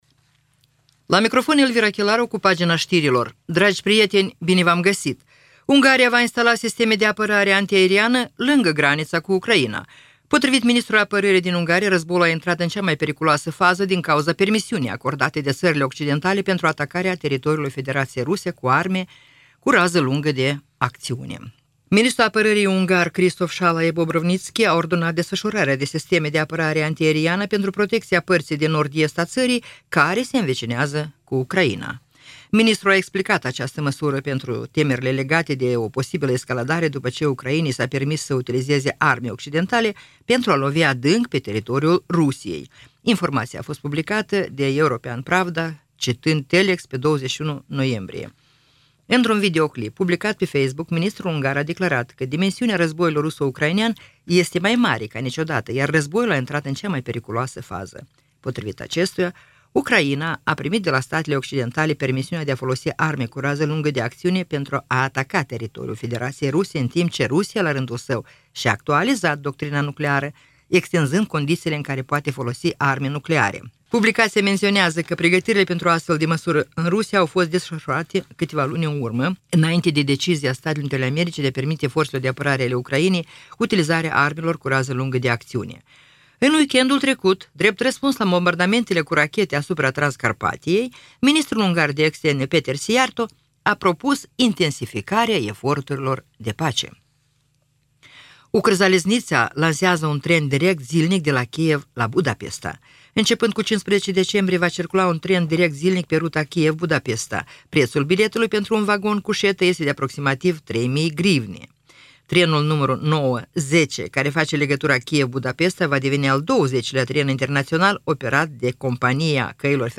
Știri Radio Ujgorod – 22.11.2024